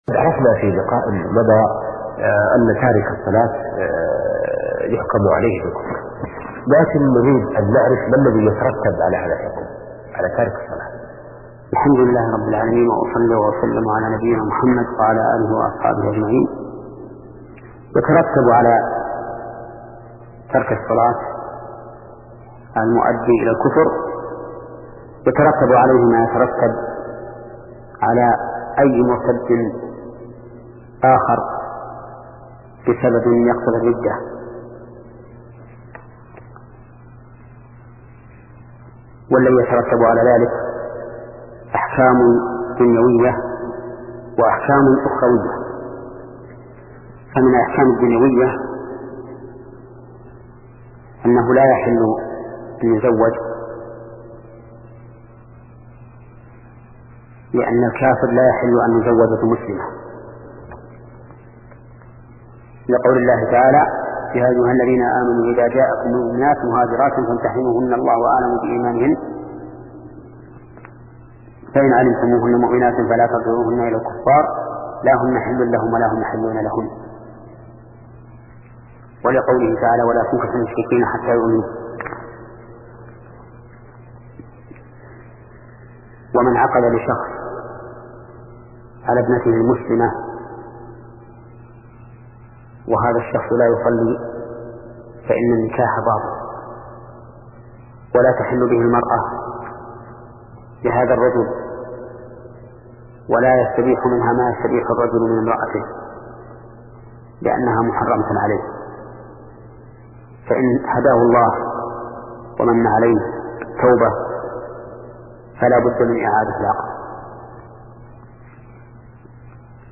شبكة المعرفة الإسلامية | الدروس | فقه العبادات (23) |محمد بن صالح العثيمين
فقه العبادات [23] - للشيخ : ( محمد بن صالح العثيمين ) أجمع السلف على كفر تارك الصلاة، ويترتب على الكفر أحكام دنيوية: كحرمة تزويجه، وسقوط ولايته، وسقوط حقه في الحضانة والإرث، ودخوله حرم الله..